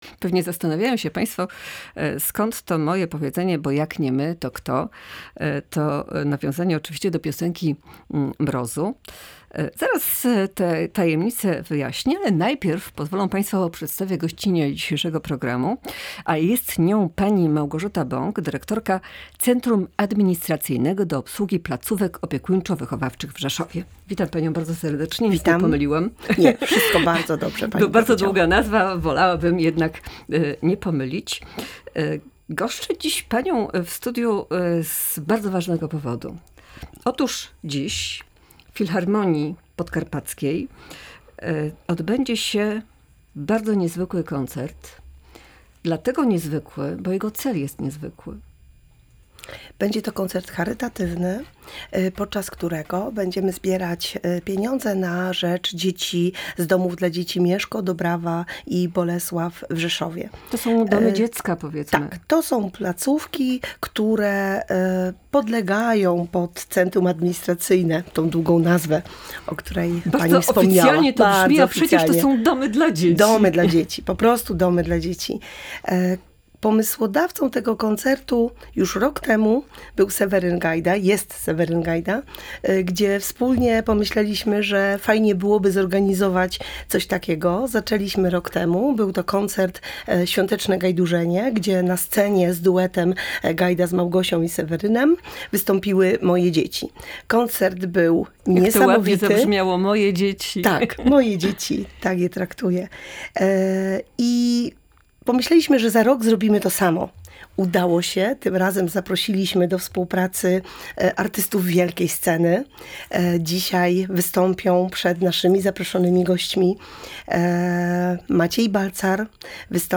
W audycji „Tu i Teraz” rozmawialiśmy o tym, jak ważna jest dla innych nasza, choćby najmniejsza, pomoc. Opowiedzieliśmy o koncercie charytatywnym na rzecz dzieci z domów dziecka pod hasłem „Bo Jak Nie My To Kto”, który odbędzie się dzisiaj o 19:00 w Filharmonii Podkarpackiej.